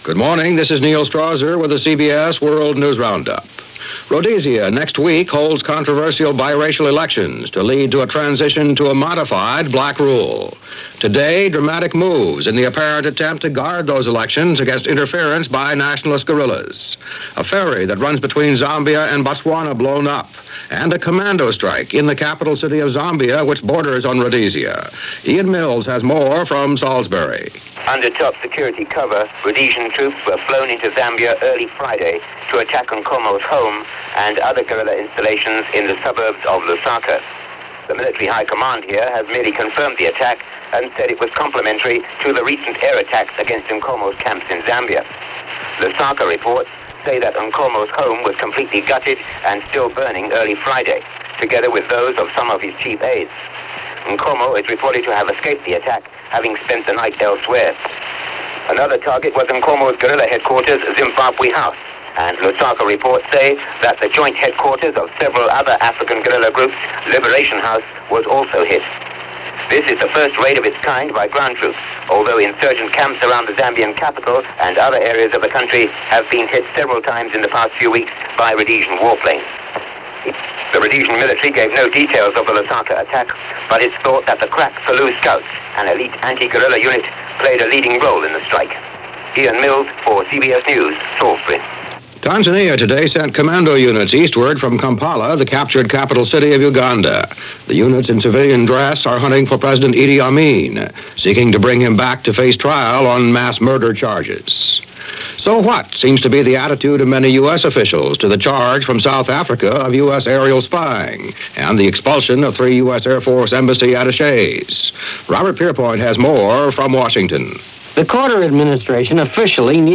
News for this day as reported on The CBS World News Roundup.